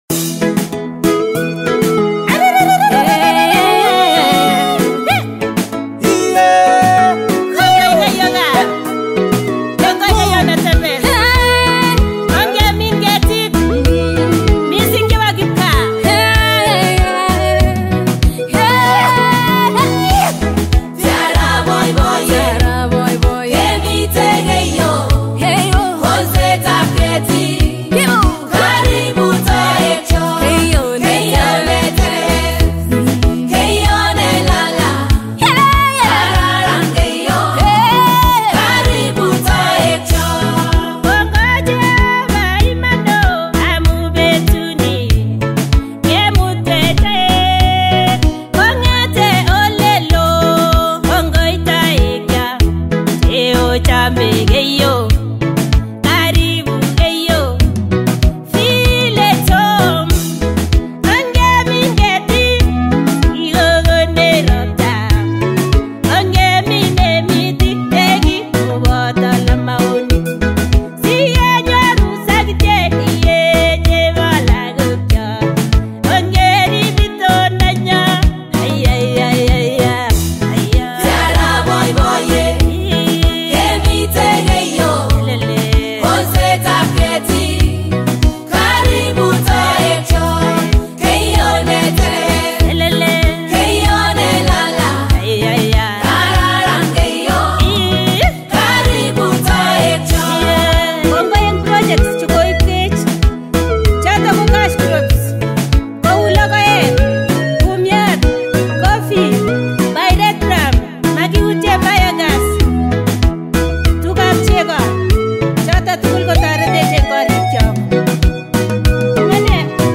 Cultural